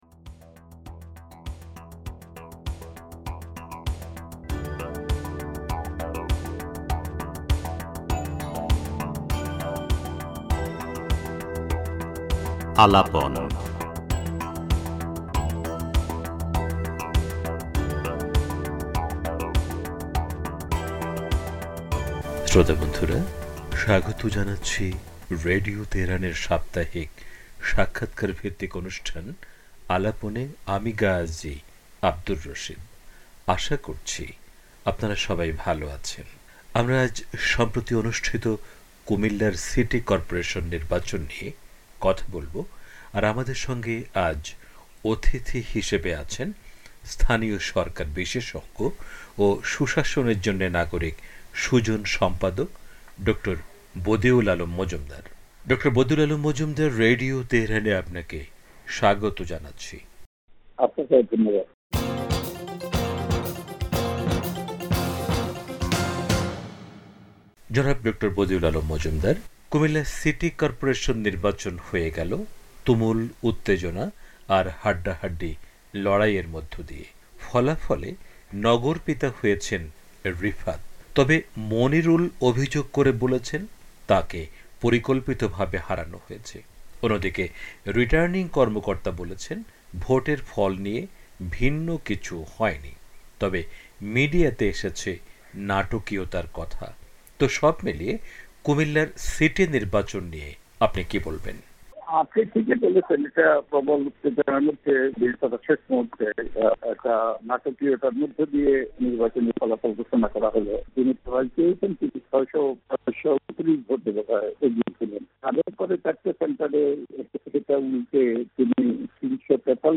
পুরো সাক্ষাৎকারটি তুলে দেয়া হলো।